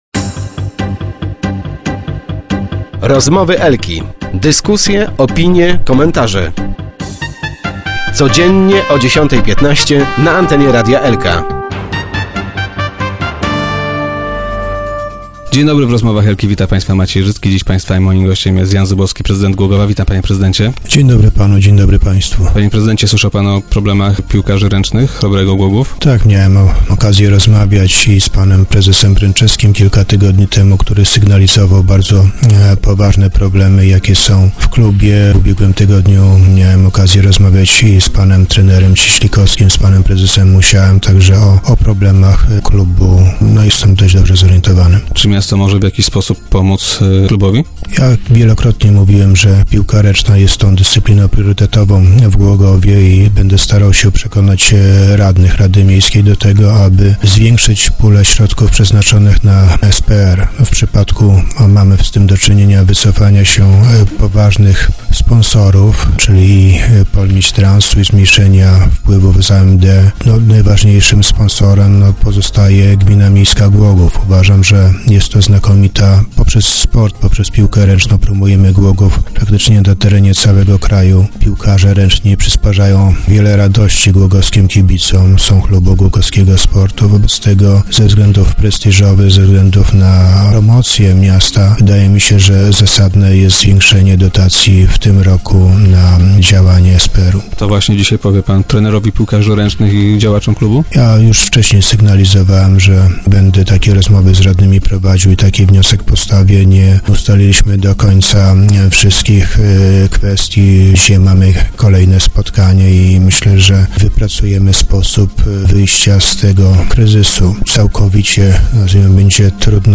- Znam sytuację i jestem za tym, by miasto zwiększyło dotację dla klubu - powiedział dziś na antenie Radia Elka prezydent Jan Zubowski.